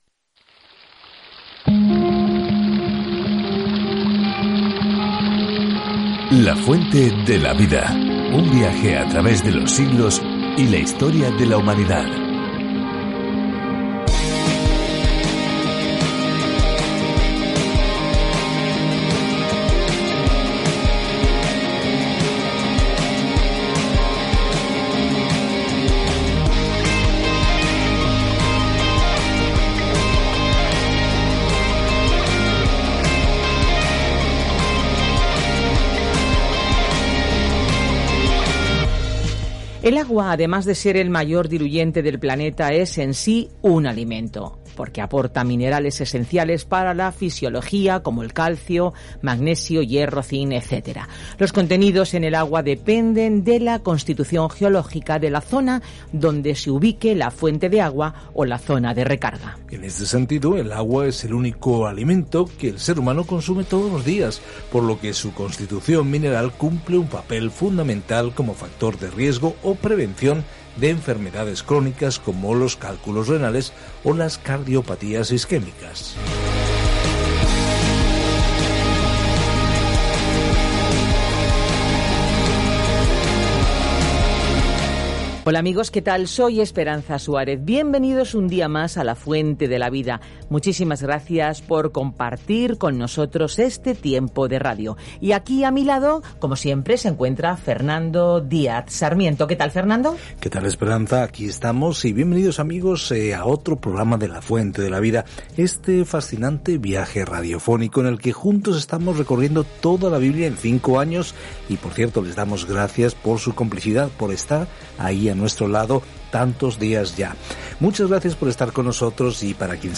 Escritura ZACARÍAS 9:5-9 Día 20 Iniciar plan Día 22 Acerca de este Plan El profeta Zacarías comparte visiones de las promesas de Dios para dar a las personas una esperanza en el futuro y las insta a regresar a Dios. Viaja diariamente a través de Zacarías mientras escuchas el estudio en audio y lees versículos seleccionados de la palabra de Dios.